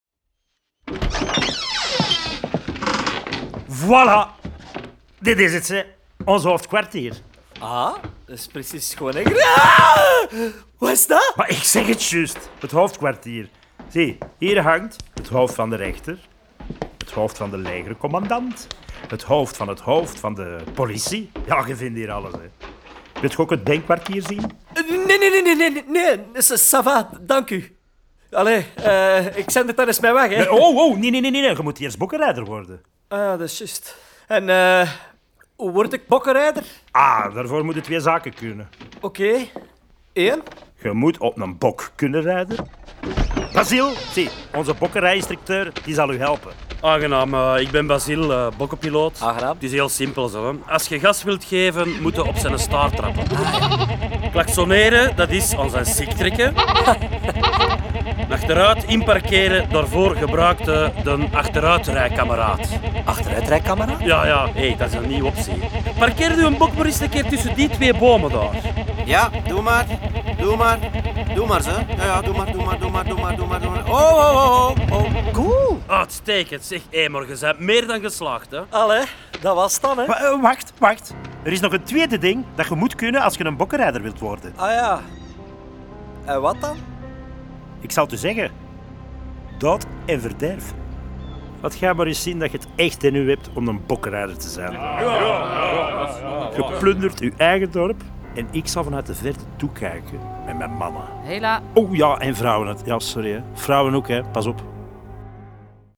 De Bokrokker is niet alleen een boek om te lezen, maar ook een hoorspel boordevol grappen en vrolijke liedjes.